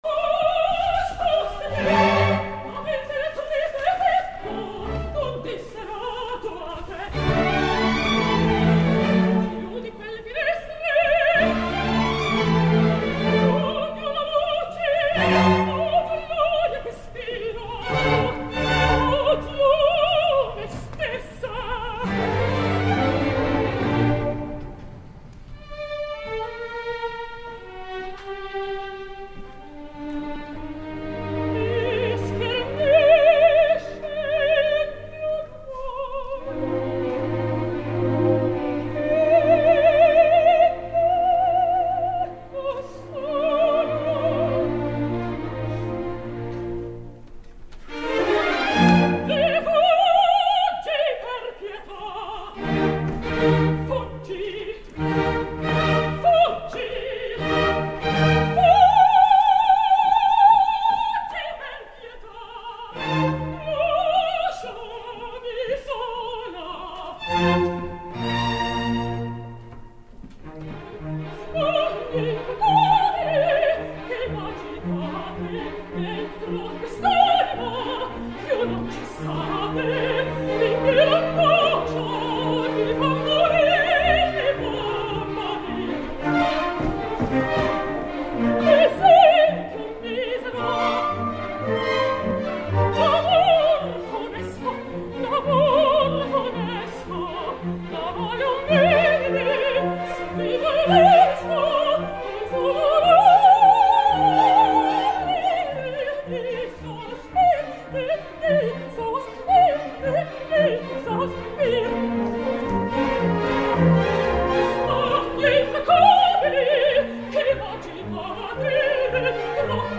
airada primera ària
LA Opera, 18 de setembre de 2011.